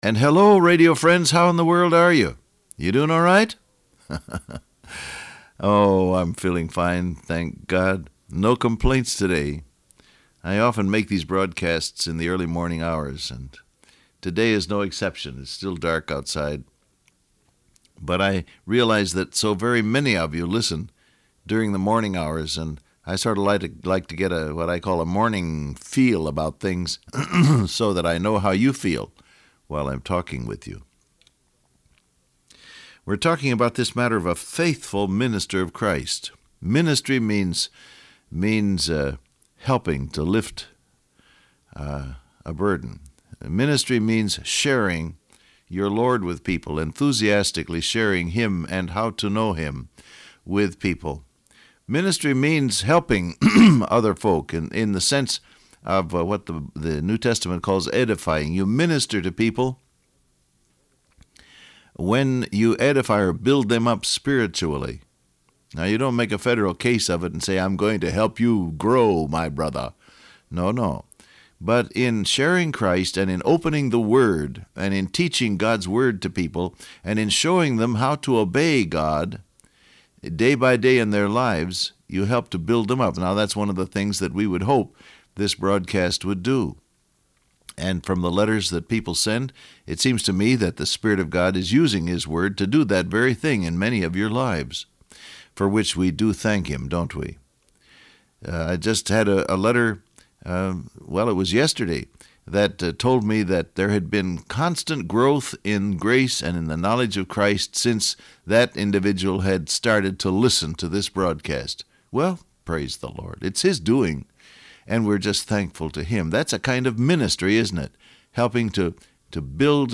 Download Audio Print Broadcast #6497 Scripture: Colossians 1:7 , 2 Corinthians 5:18-20, Romans 8 Topics: Ministry , Share , Proclaim Transcript Facebook Twitter WhatsApp And hello radio friends!